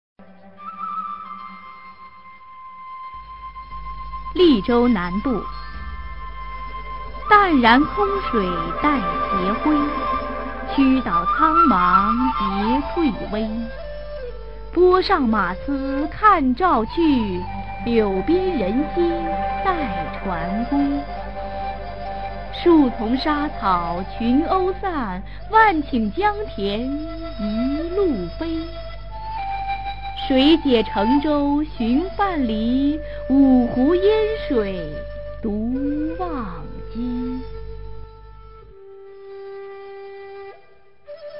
[隋唐诗词诵读]温庭筠-利州南渡a 配乐诗朗诵